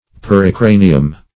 Search Result for " pericranium" : The Collaborative International Dictionary of English v.0.48: Pericranium \Per`i*cra"ni*um\, n. [NL.]